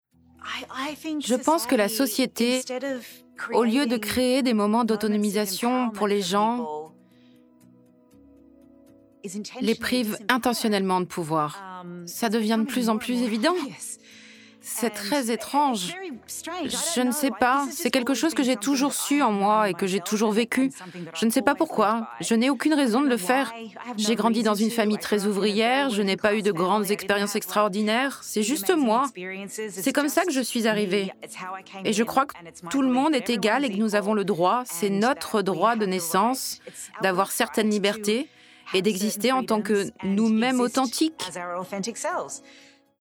Ma voix est celle d’une femme entre trente et quarante ans qui a du coeur, de l’humanité, de la luminosité et une douceur qui convient par exemple très bien à la narration d’un drame historique.
Voice-over interview anglais/français : How Toni Collette broke Hollywood’s rules.